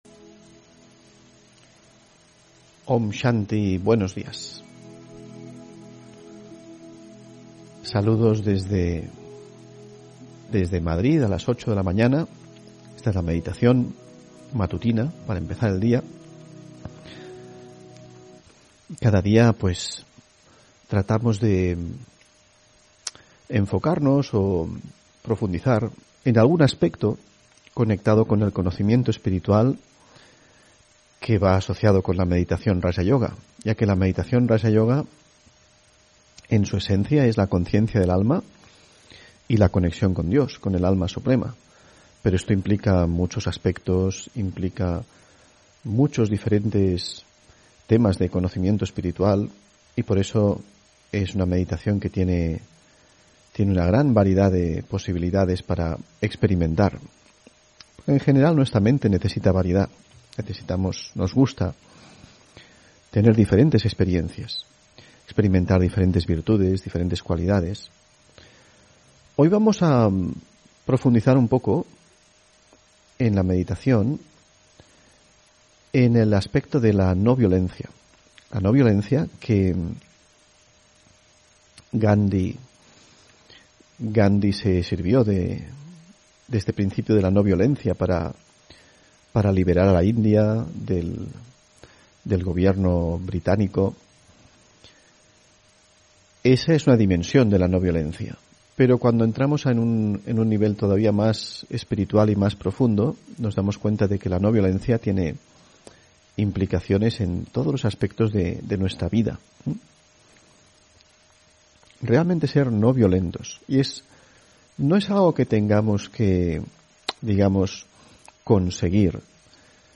Meditación de la mañana